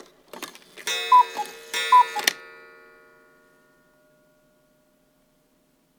cuckoo_strike2.L.wav